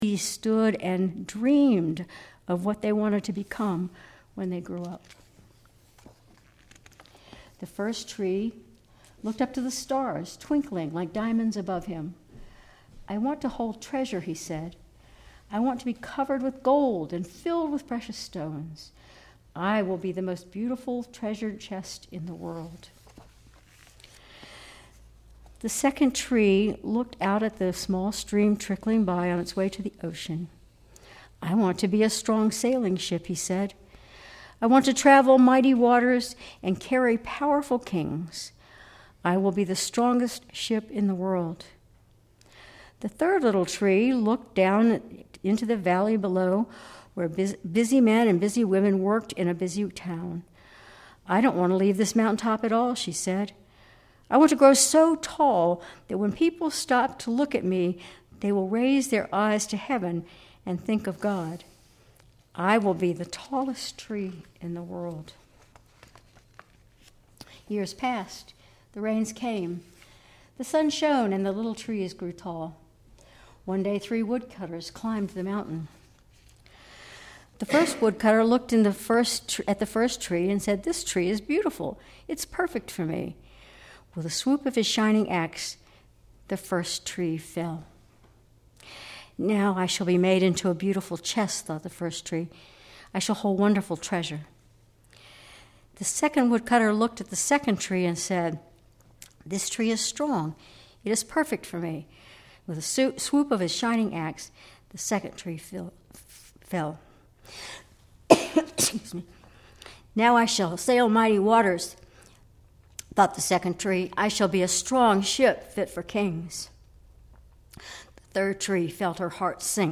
Sermon April 13, 2025